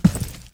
FootstepHeavy_Concrete 02.wav